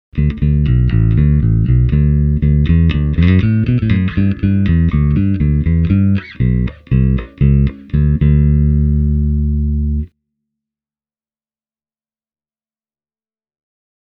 Tältä basso kuulostaa soitettuna SansAmp Bass Driver DI:n läpi:
tallamikrofoni – sormisoitto
lakland-skyline-44-60-bridge-pu-finger.mp3